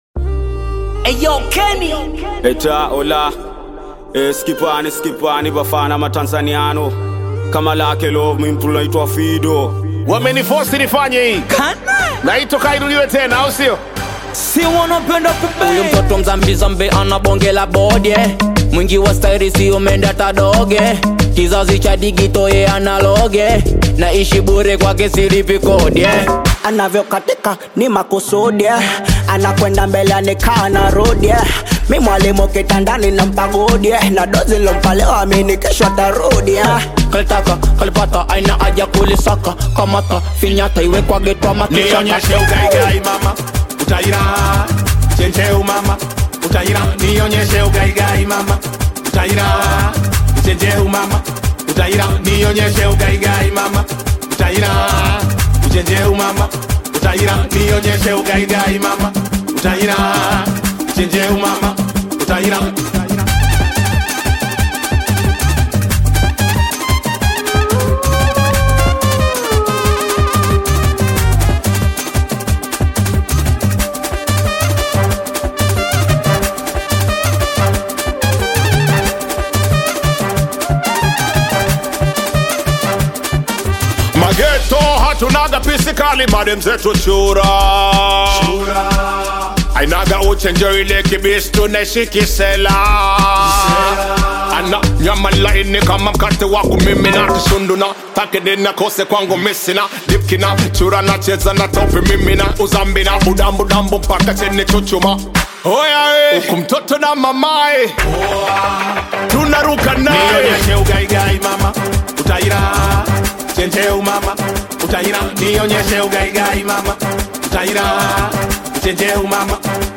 Tanzanian Bongo Flava artists
Bongo Flava You may also like